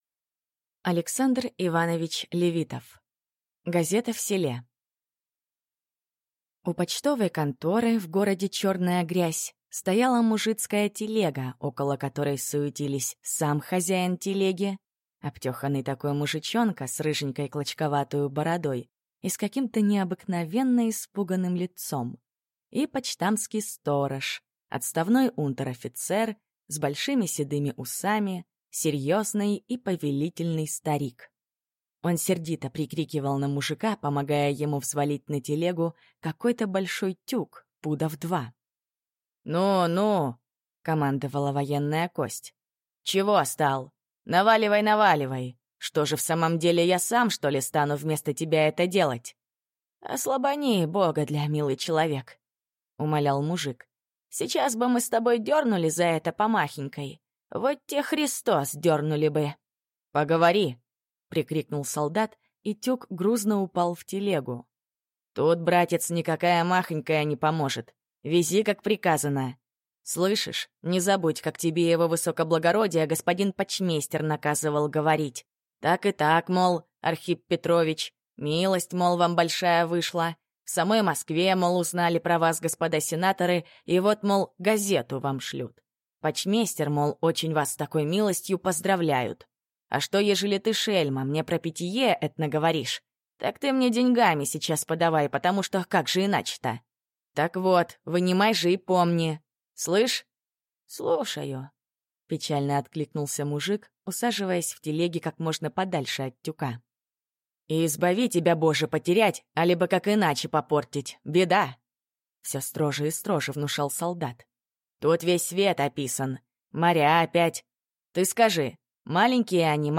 Аудиокнига Газета в селе | Библиотека аудиокниг
Прослушать и бесплатно скачать фрагмент аудиокниги